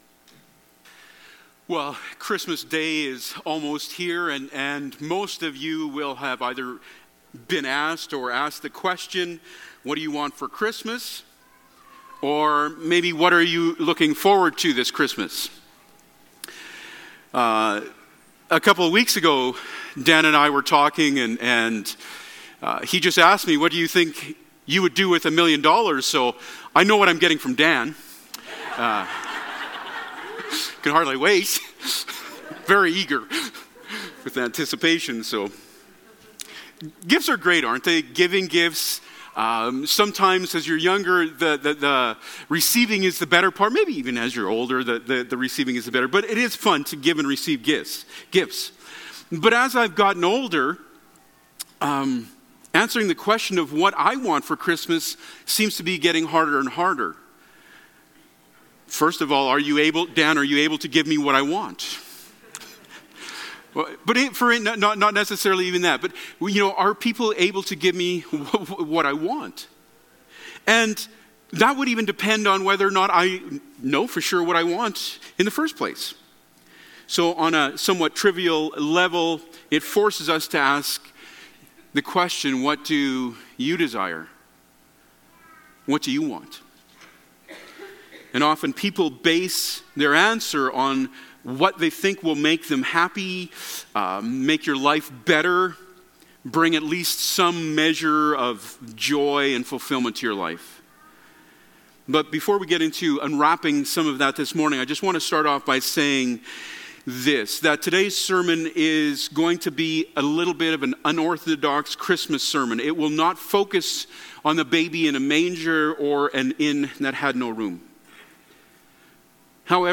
Passage: John 5:2-9, 13-14 Service Type: Sunday Morning Topics